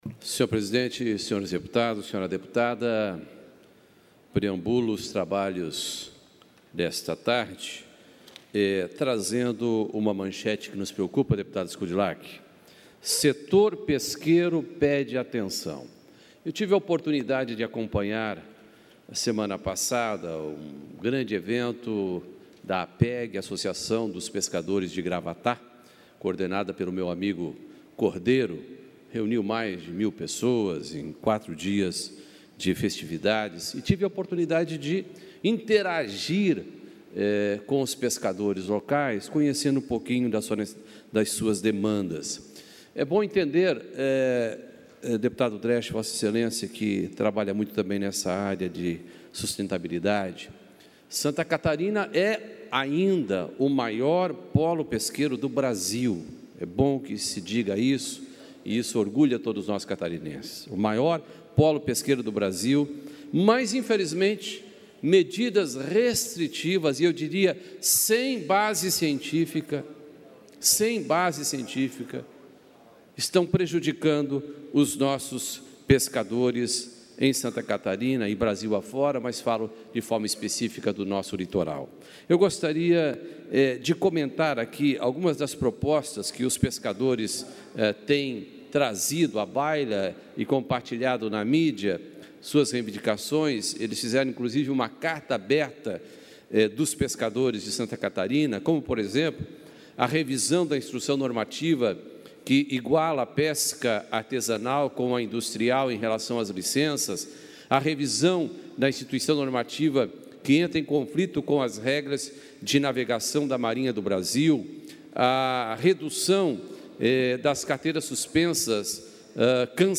Confira aqui o pronunciamento dos deputados em tribuna, durante a sessão ordinária desta quarta-feira (26):
deputado Dirceu Dresch (PT) - pronunciamento 1 -